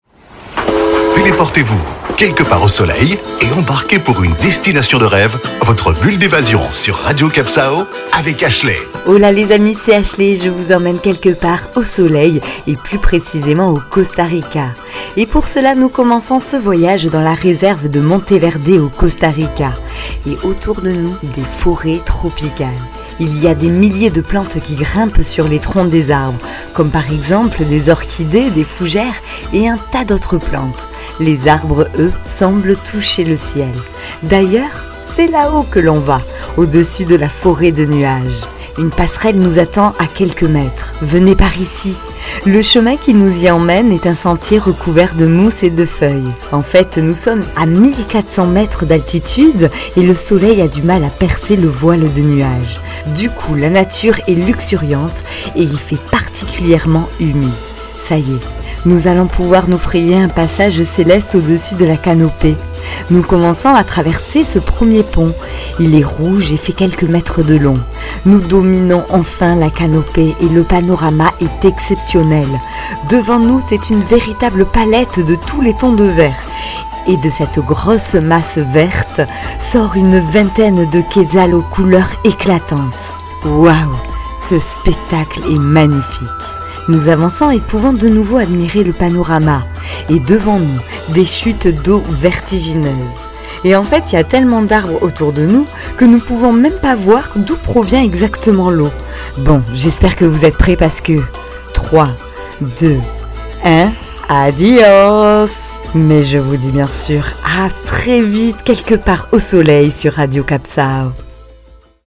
Carte postale sonore : engouffrez-vous dans la jungle luxuriante du Costa Rica et jouez aux Indiana Jones le temps d'une chronique.